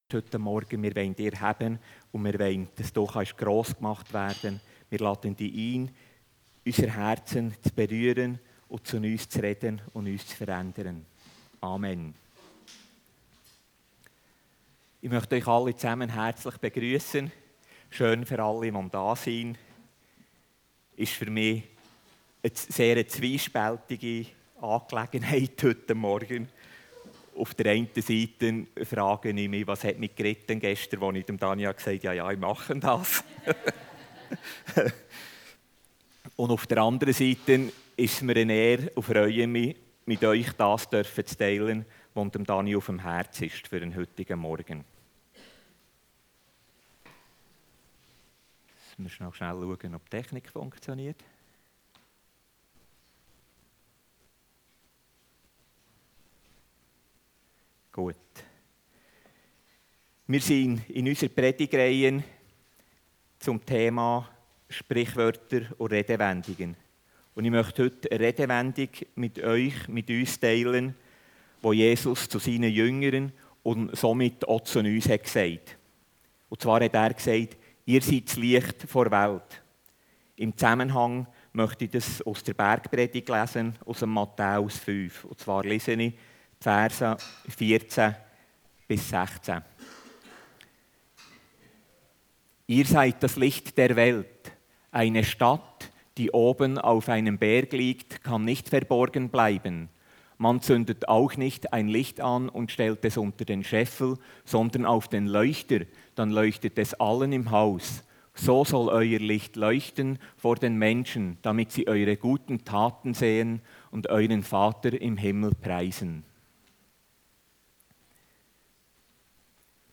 Passage: Matthäus 5, 14-16 Dienstart: Gottesdienst